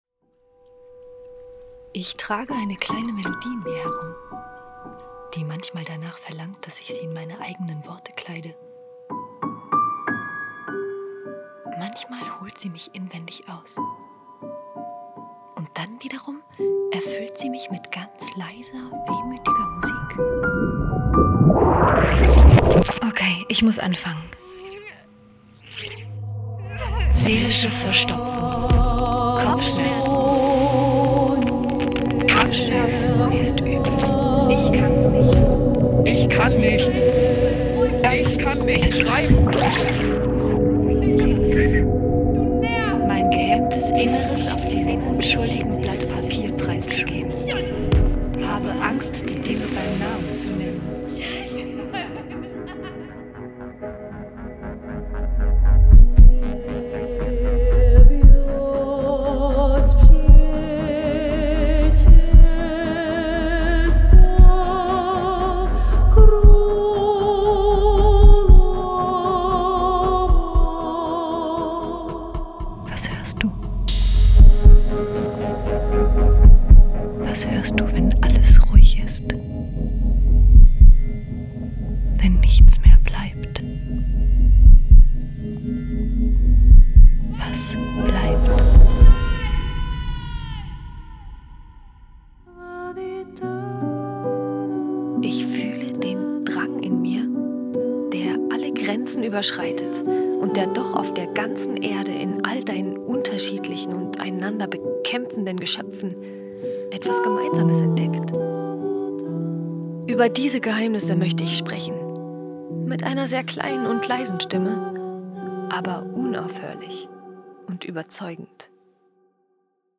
Überraschend humorvoll und hemmungslos ehrlich schildert sie ihren Weg – ein Ringen um Mitgefühl, Liebe und Zuversicht. Elektronisch-musikalische Text- und Stimmlandschaften geben diesem Hörstück eine sinnliche Form und schaffen Raum für eine bewegende Denkerin, die dem deutschsprachigen Publikum bis zum heutigen Tag nahezu unbekannt geblieben ist.
Des paysages sonores mêlant texte, voix, musique instrumentale et électronique composent cette pièce qui redonne toute sa place une penseuse émouvante, restée jusqu’à présent quasi inconnue dans l’espace germanophone.